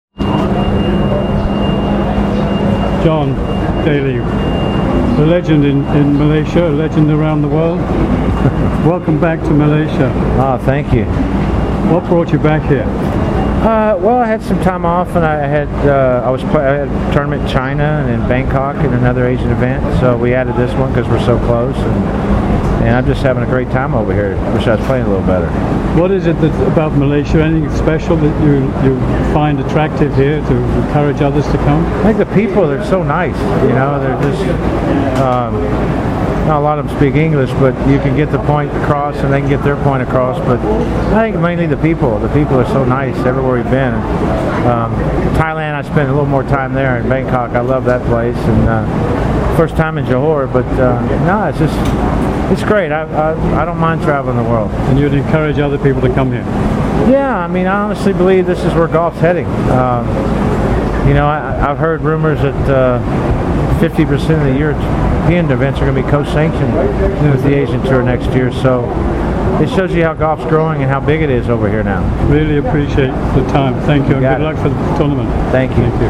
MGTA interviews John Daly